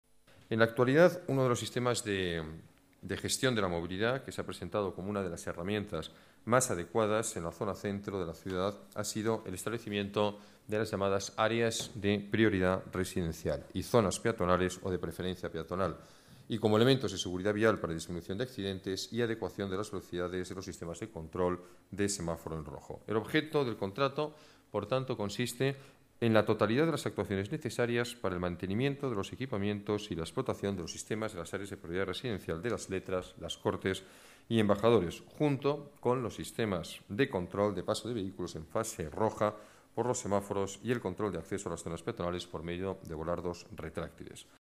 Nueva ventana:Declaraciones del alcalde de Madrid; Alberto Ruiz-Gallardón: Controles de acceso más modernos y eficaces